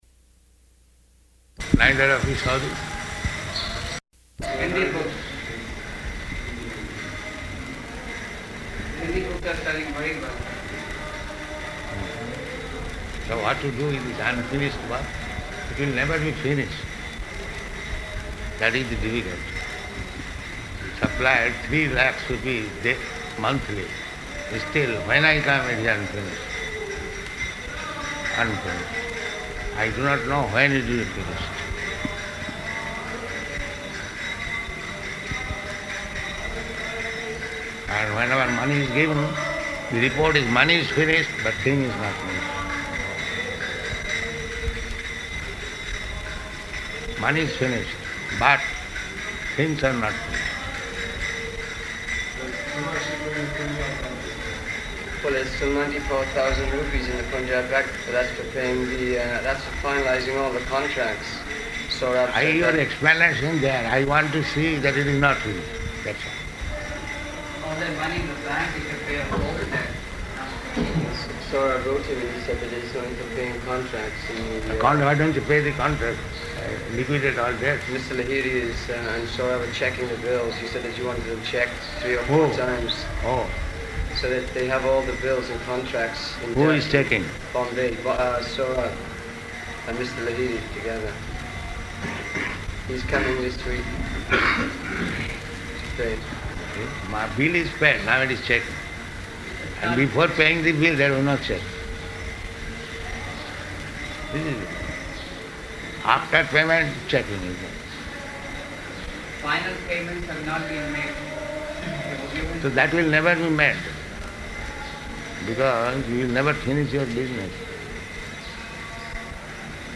Room Conversation
Room Conversation --:-- --:-- Type: Conversation Dated: August 26th 1975 Location: Vṛndāvana Audio file: 750826R1.VRN.mp3 Prabhupāda: Neither of you saw this?